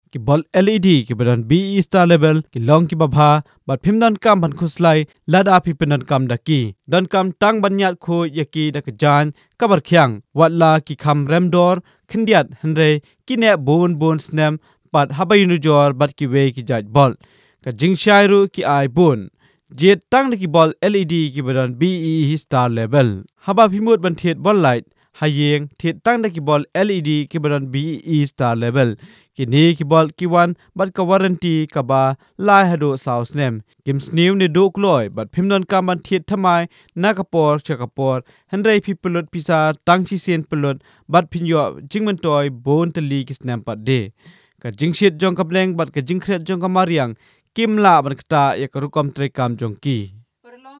Khasi Male
Professional male Khasi voice artist.........
Khasi Male 2 .........